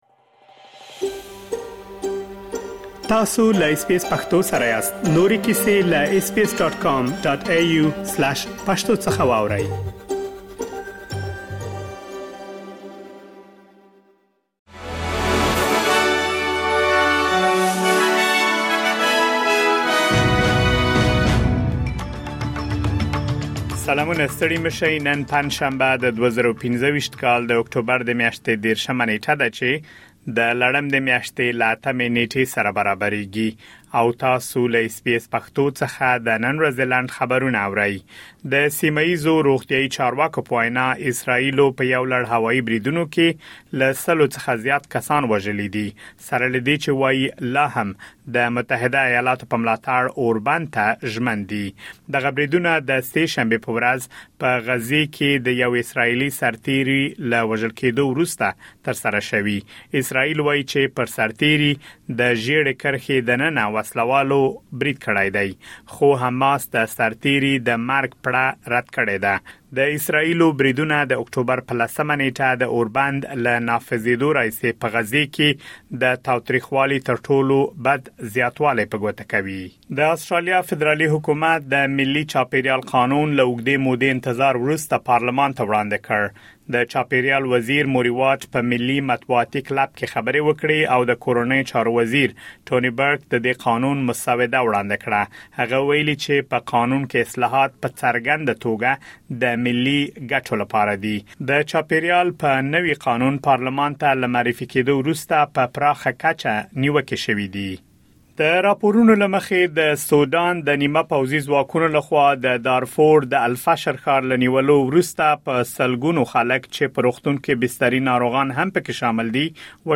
د اس بي اس پښتو د نن ورځې لنډ خبرونه |۳۰ اکټوبر ۲۰۲۵